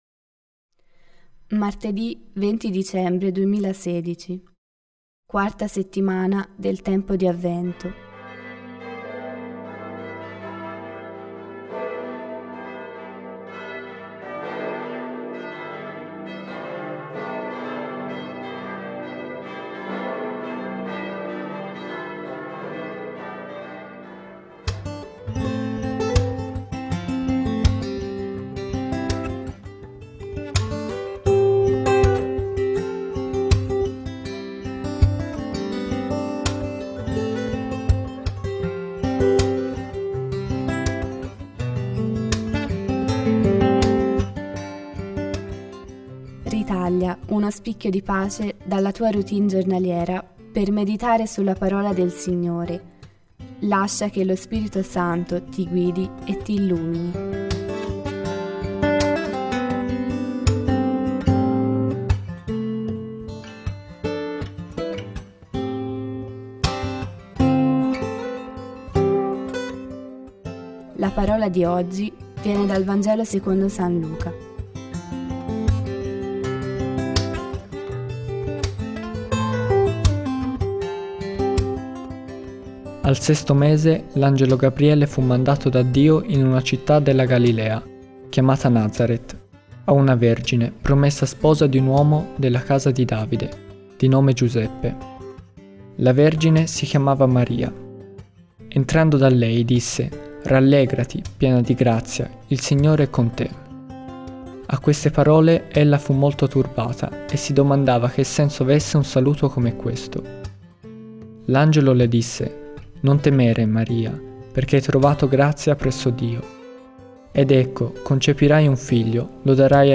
Musica: Guitar, Rylynn – Andy McKee